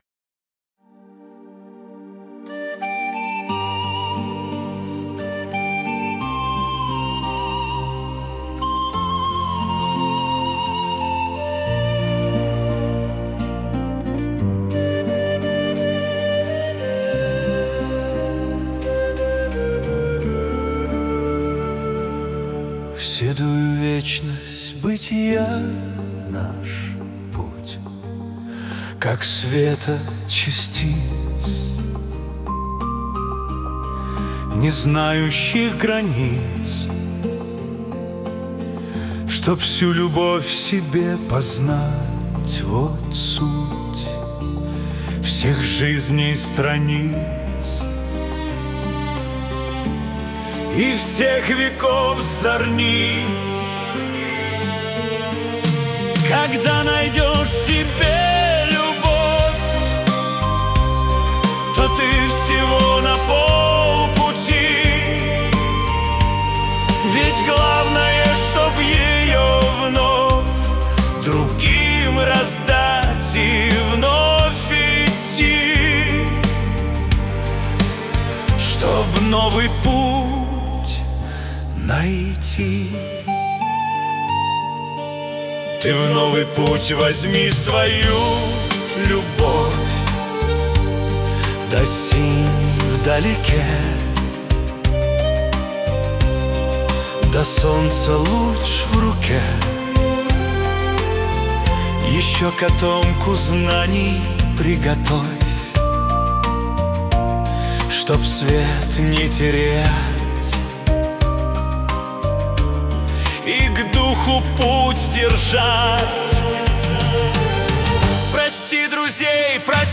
Для Медитаций